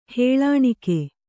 ♪ hēḷāṇike